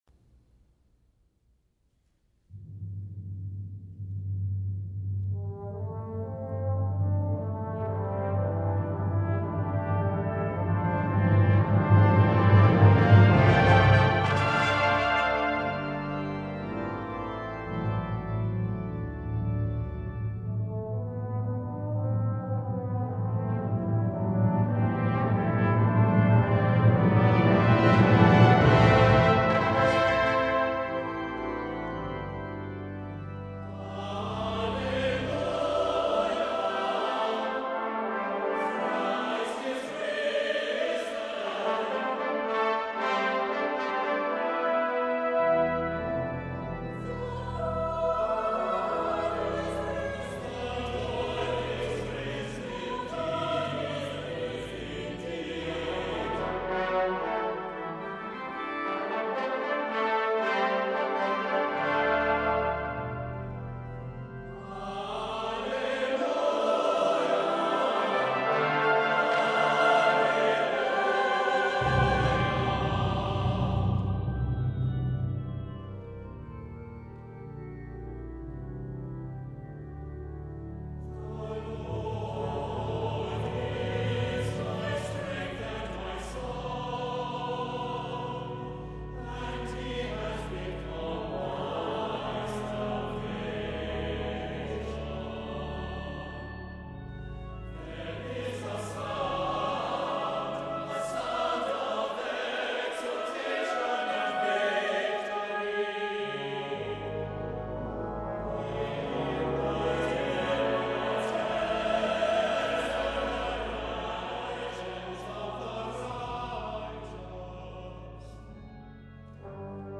• scored for organ, four-part choir, brass and timpani
There are passages with the choir in two parts or unison.